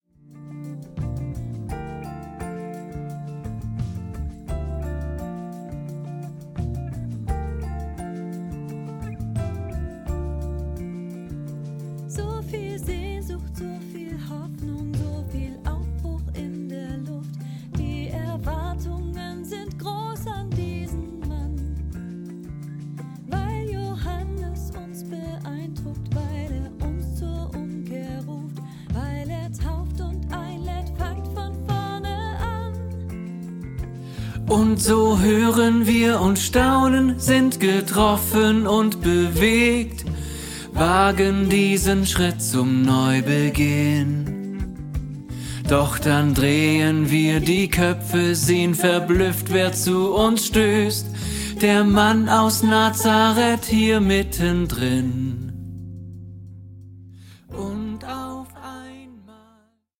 Separate Aufnahmen mit hervorgehobenen Tenor-Stimmen.